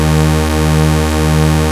OSCAR 1  D#3.wav